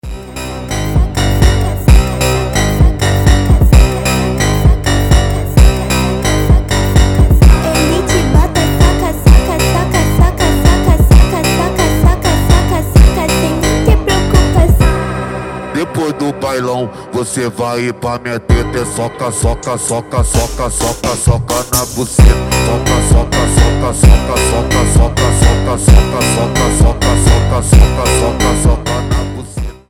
• Качество: 320, Stereo
мощные басы